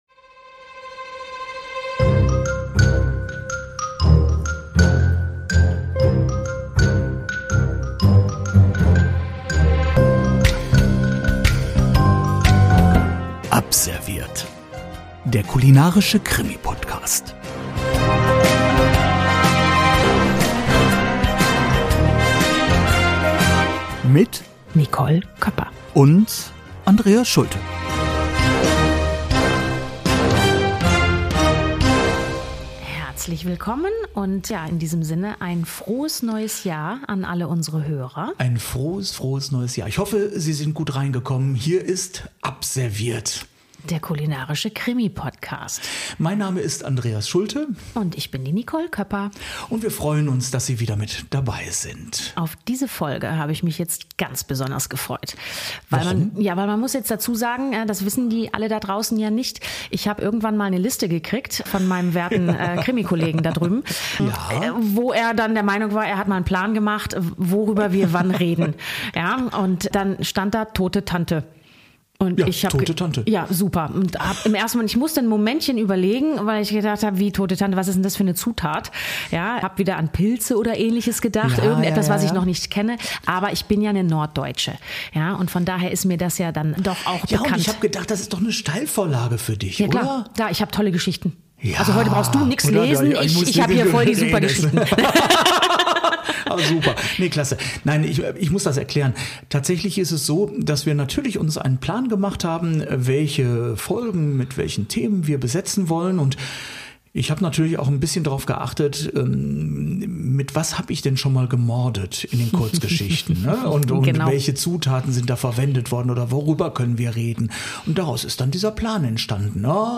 plaudern diesmal über Heißgetränke wie Tote Tante, Grog, Glühwein & Co. Wussten Sie woher der Name Tote Tante kommt?